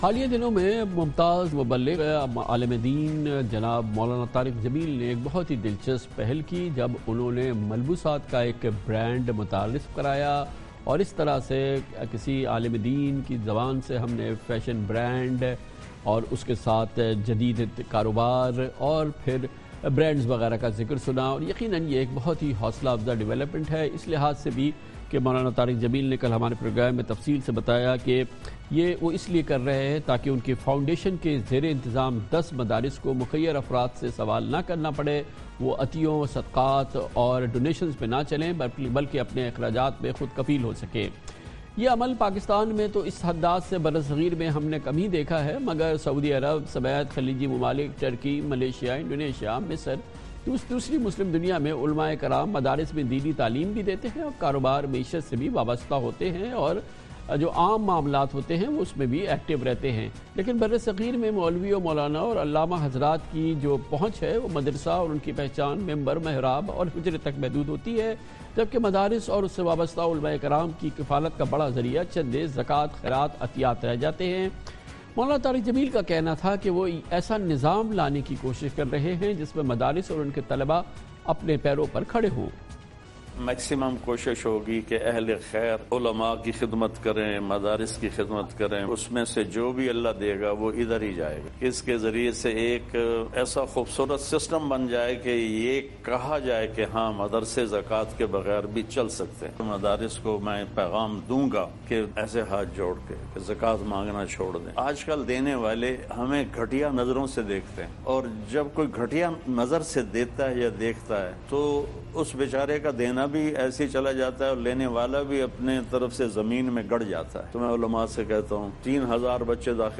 Category: TV Programs / Dunya News / Questions_Answers /